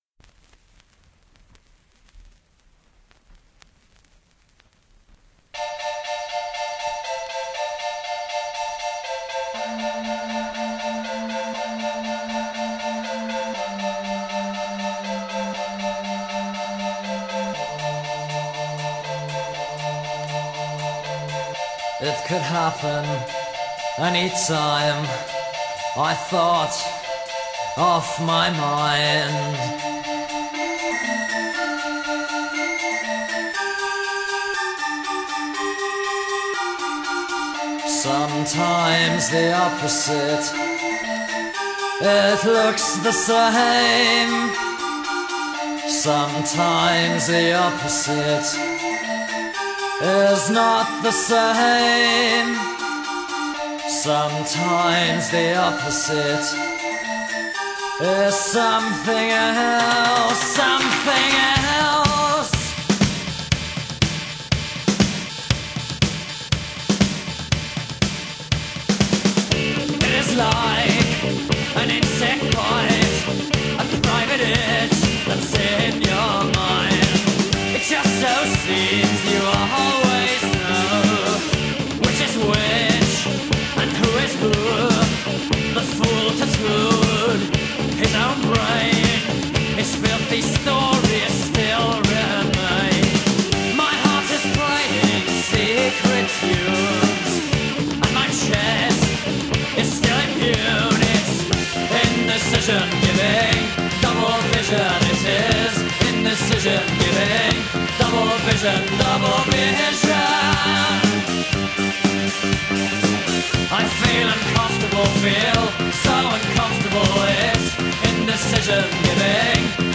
Post-Punk-Band